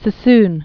(sə-sn, să-), Siegfried Lorraine 1886-1967.